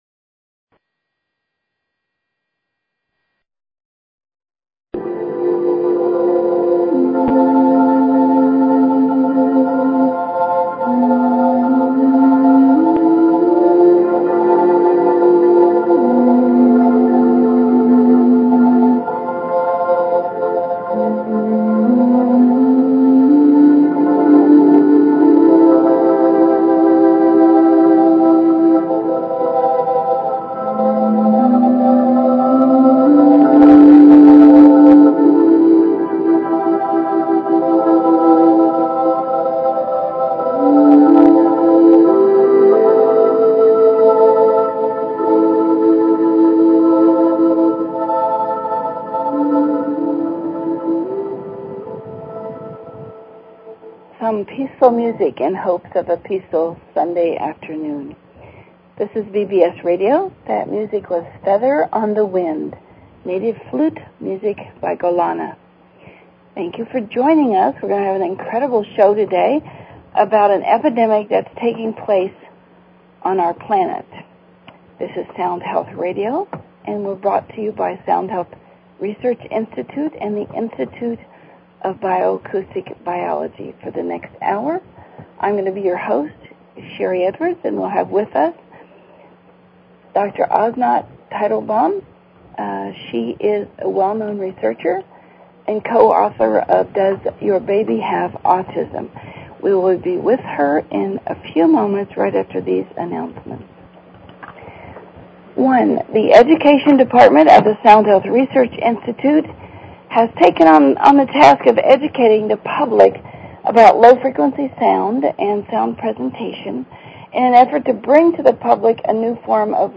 Talk Show Episode, Audio Podcast, Sound_Health and Courtesy of BBS Radio on , show guests , about , categorized as